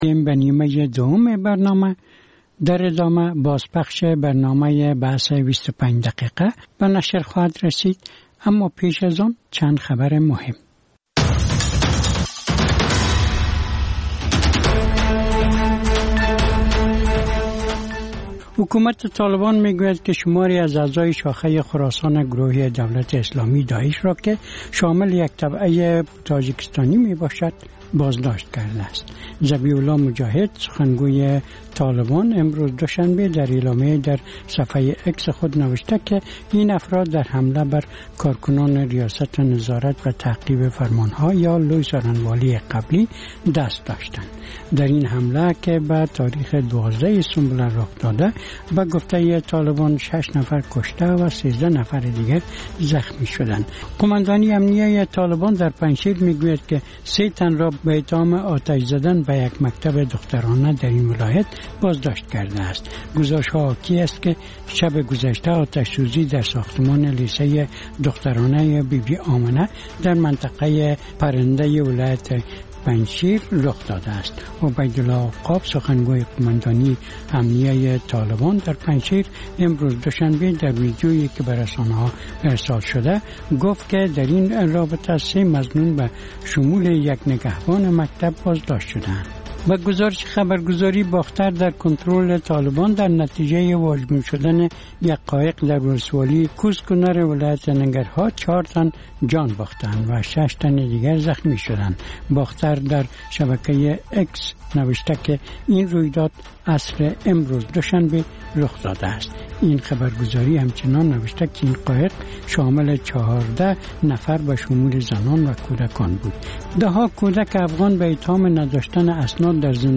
خبرهای کوتاه - میز گرد (تکرار)